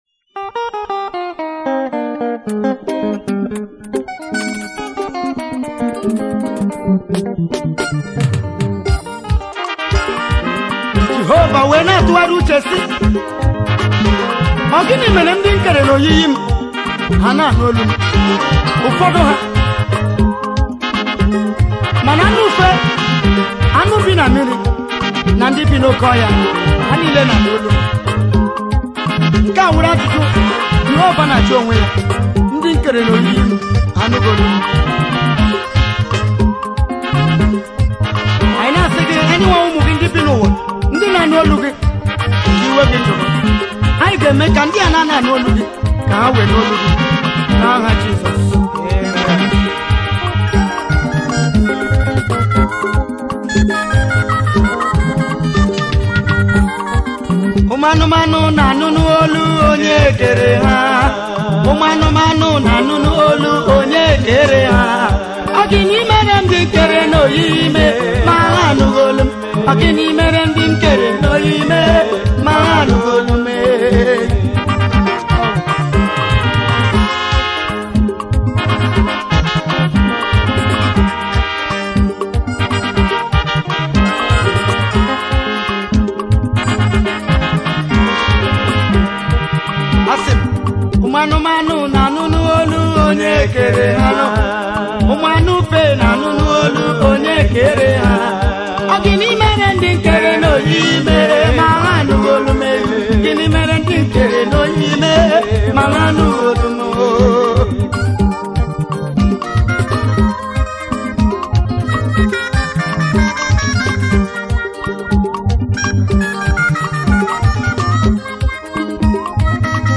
January 22, 2025 Publisher 01 Gospel 0